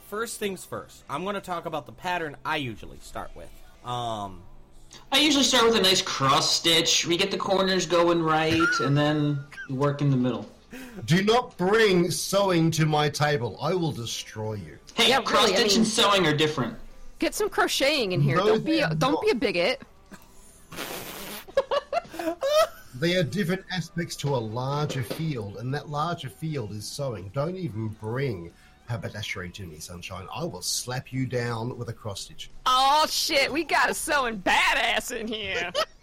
Play, download and share Sewing original sound button!!!!